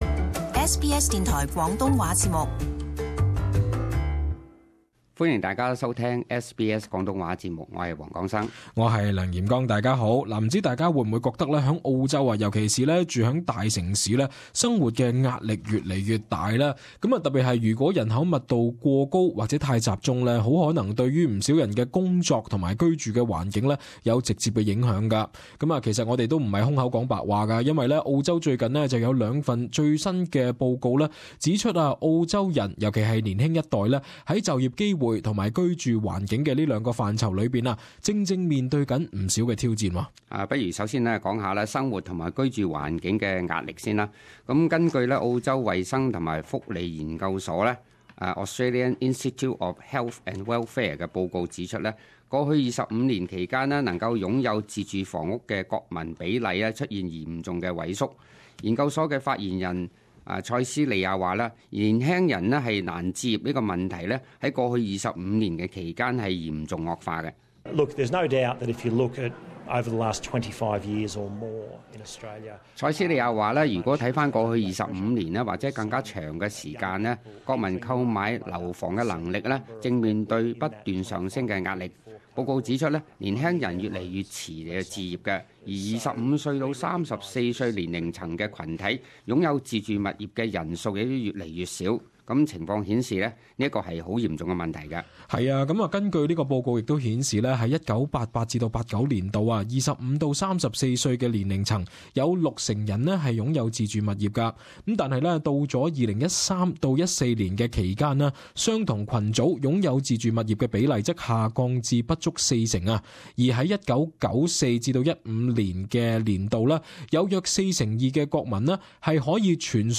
【時事報導】報告顯示澳洲就業及住屋壓力嚴重